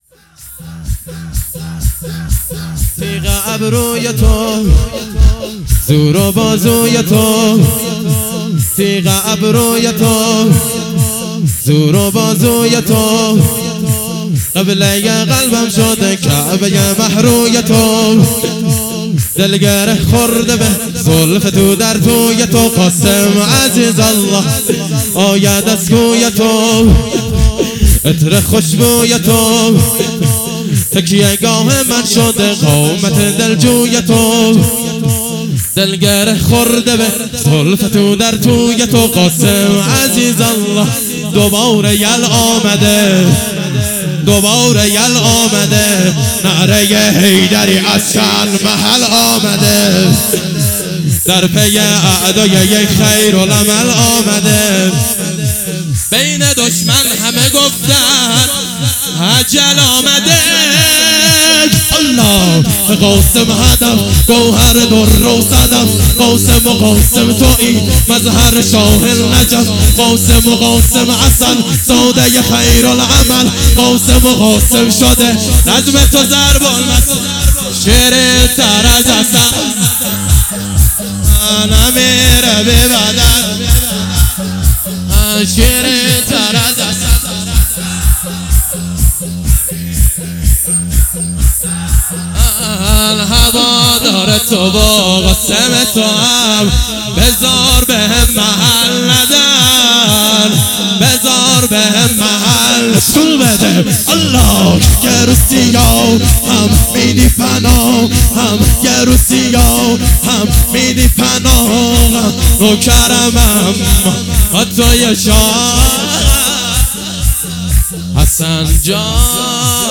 شور زیبا برای حضرت قاسم (ع)